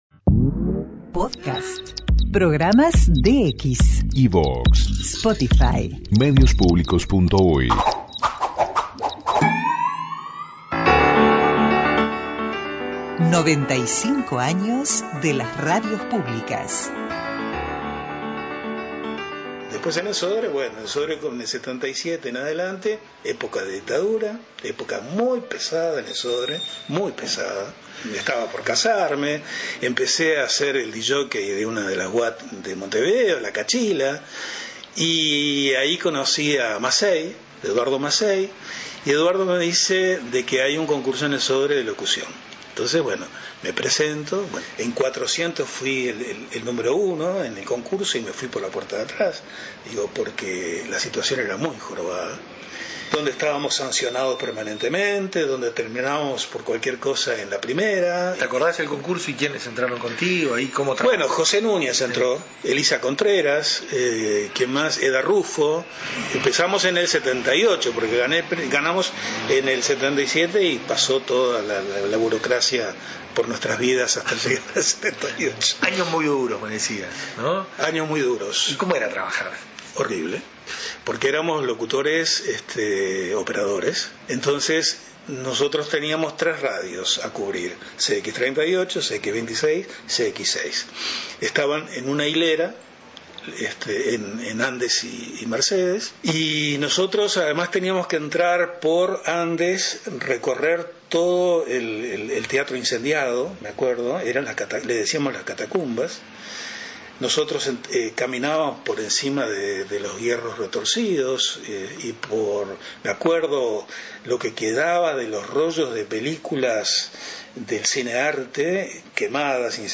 Conversatorio por los 95 Años de las Radios Públicas, segunda parte